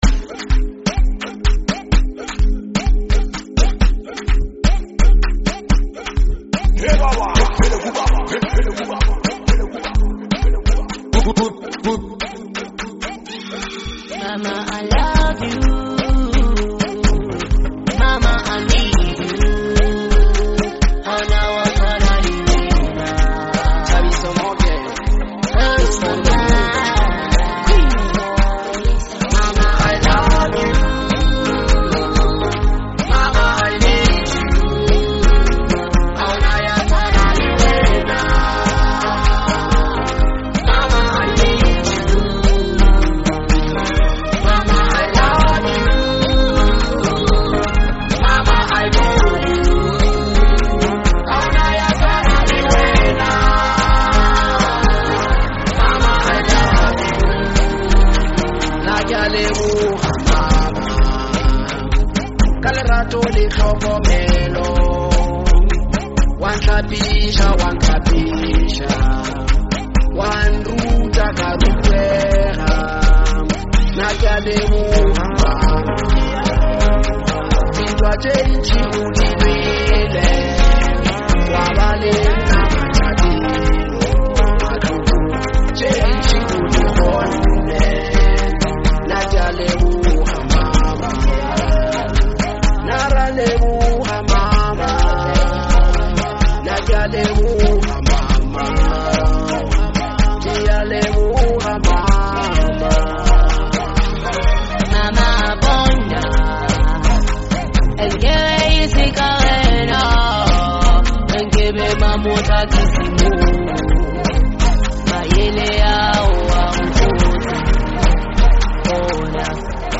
Through soulful melodies and meaningful lyrics
• Genre: Bolo House